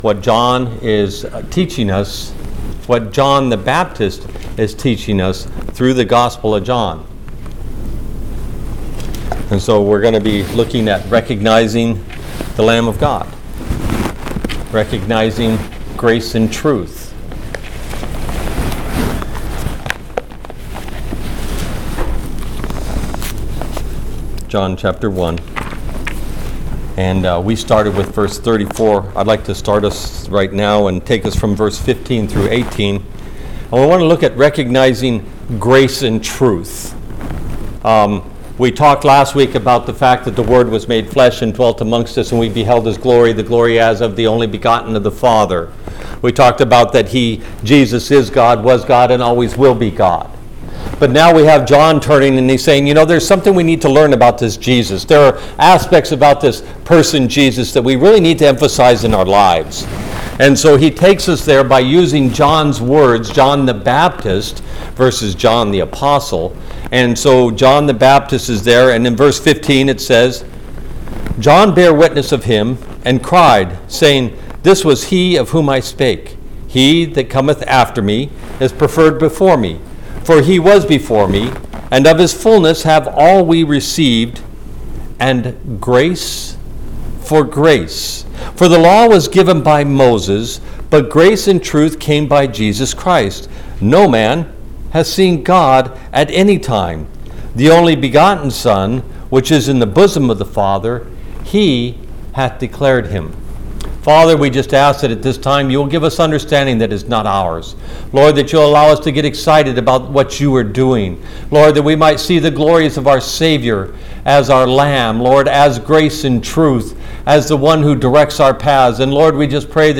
All Sermons Recognizing the Lamb of God 16 January 2022 Series: the Gospel of John Book